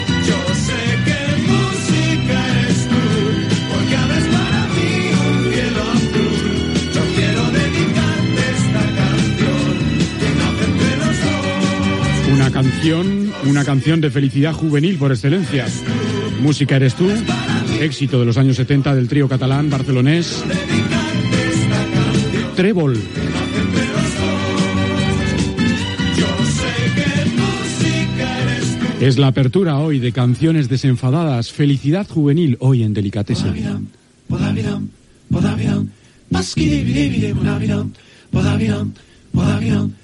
Tema musical i comentari de la cançó.
Musical